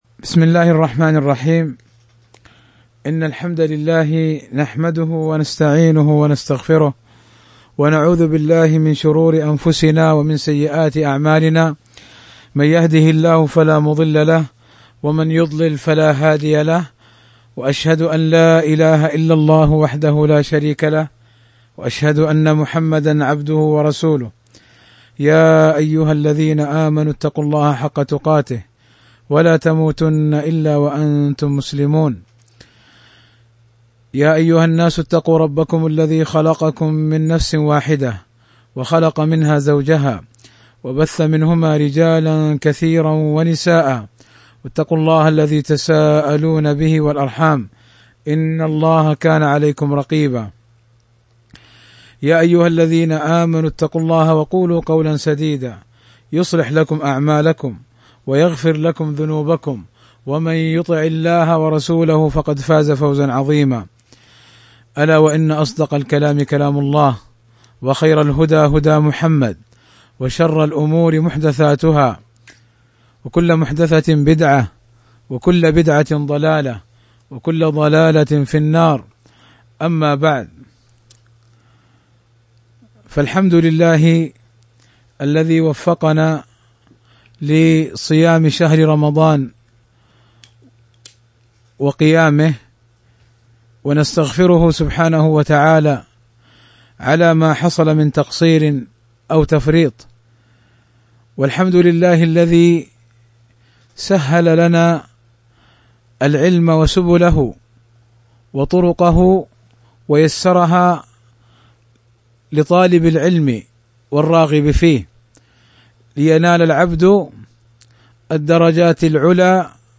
شرح رسالة في تجويد القرآن الدرس 1